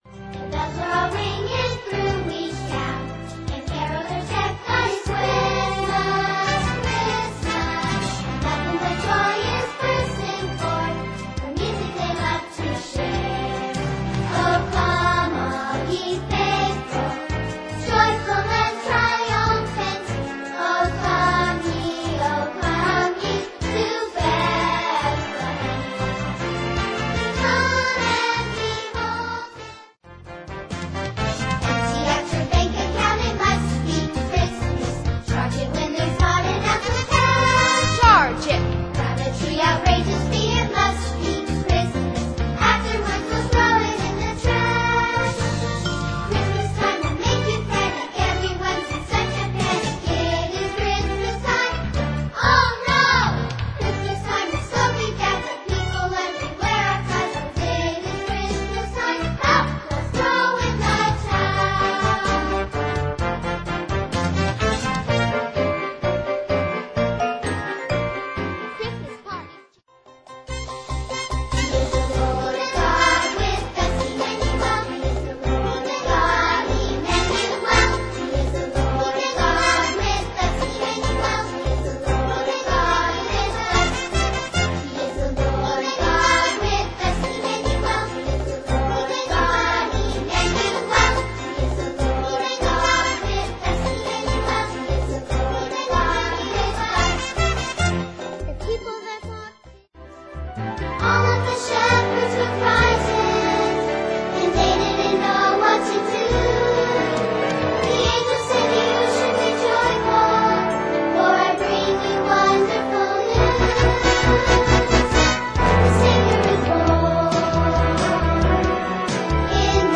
ALL SONGS PREVIEW
Calypso Emmanuel